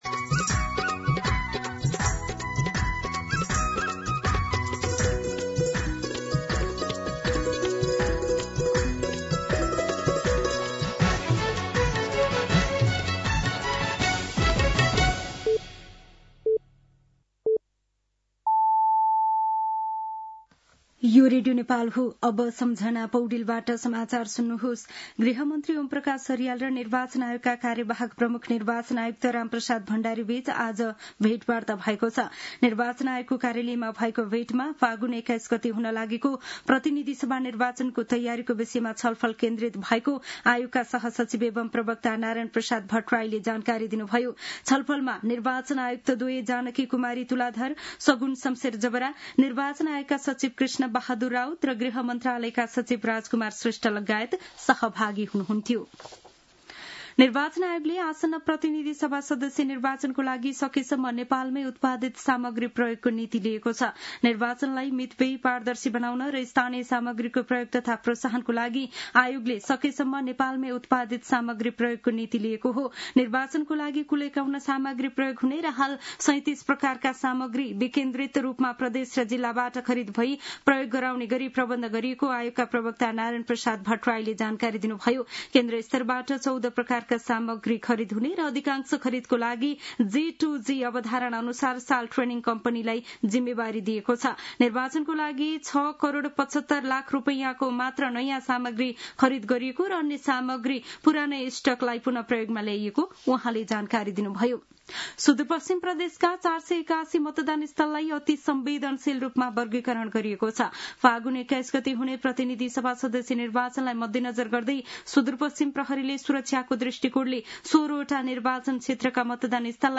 मध्यान्ह १२ बजेको नेपाली समाचार : १५ माघ , २०८२